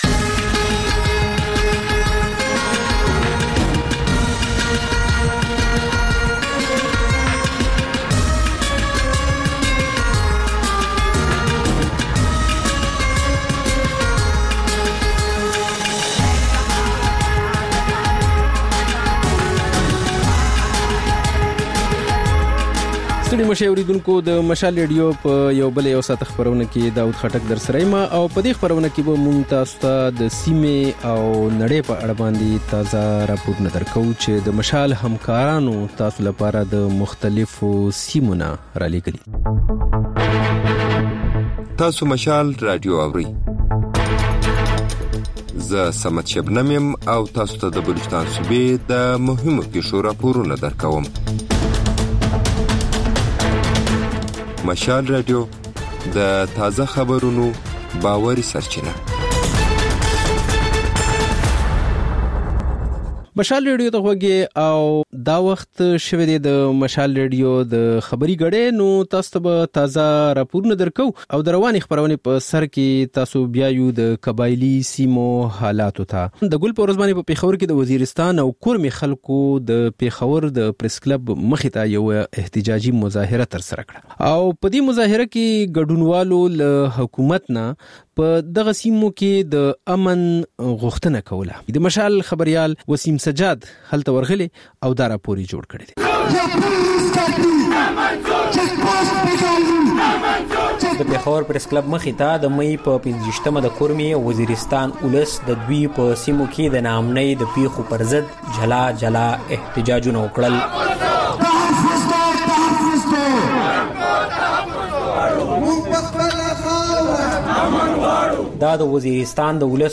په دې خپرونه کې تر خبرونو وروسته بېلا بېل رپورټونه، شننې او تبصرې اورېدای شئ.